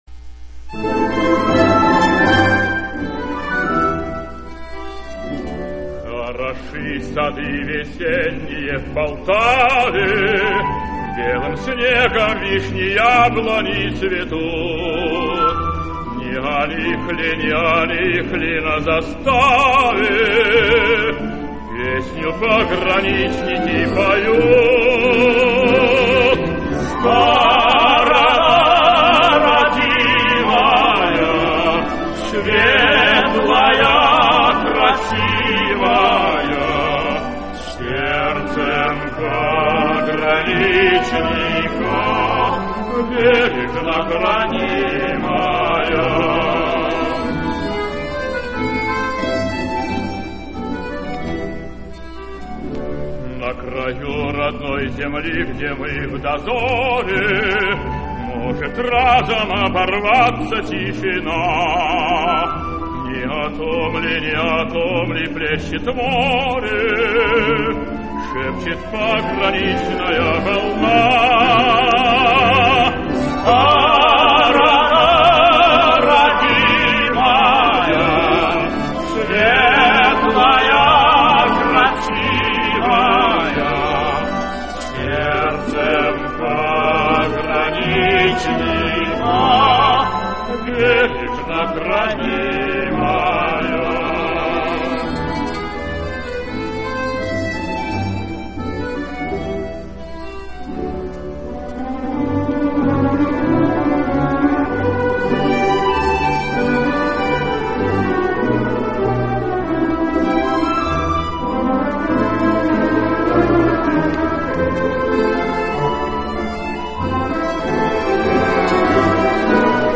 Замечательная лирико-патритическая песня.